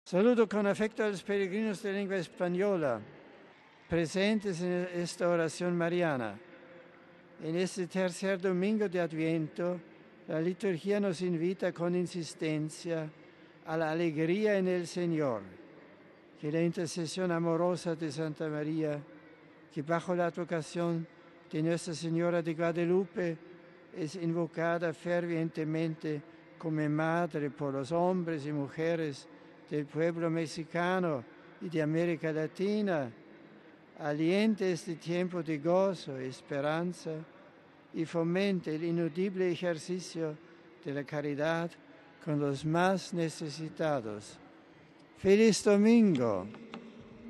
Después del rezo mariano del Ángelus el Santo Padre ha saludo en distintas lenguas.